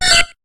Cri de Fouinette dans Pokémon HOME.